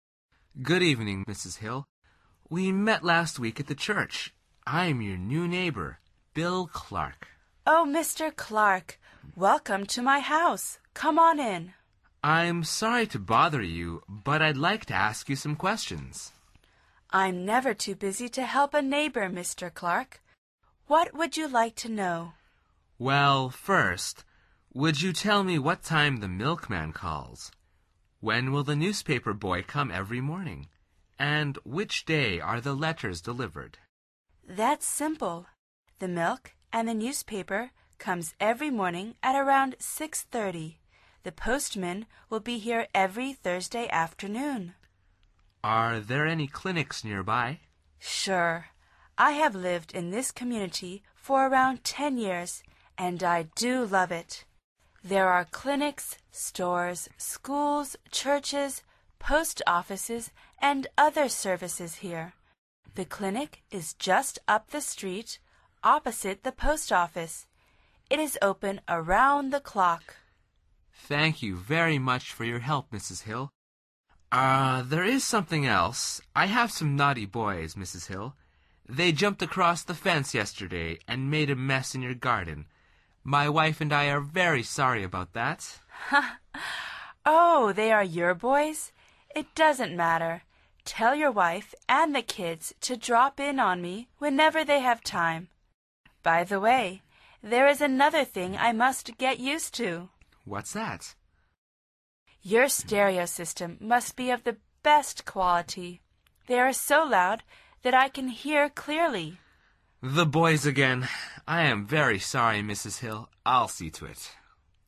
Pulsa las flechas de reproducción para escuchar el segundo diálogo de esta lección. Al final repite el diálogo en voz alta tratando de imitar la entonación de los locutores.